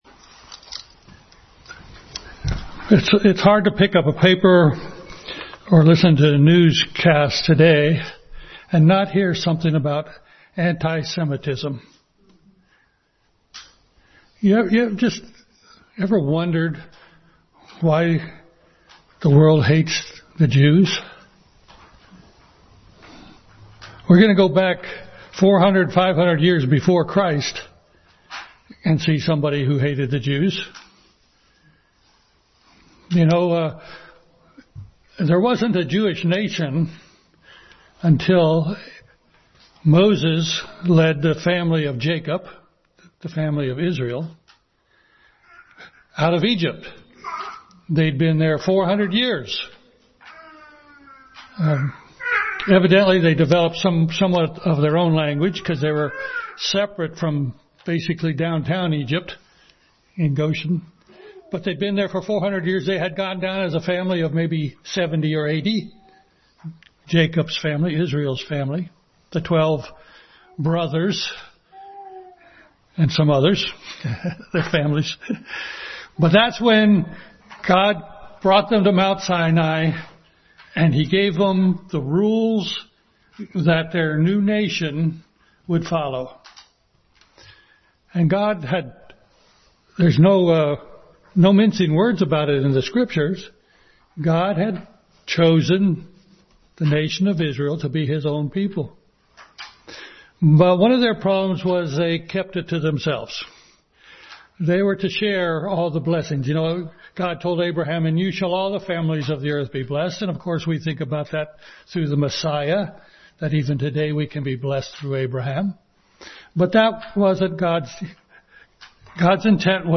A Star in Dark Times Passage: Esther 1, Revelation 2:18-28, Obadiah 1:3, Psalm 115, Colossians 4:12-15, 2 Thessalonians 2:4-5 Service Type: Family Bible Hour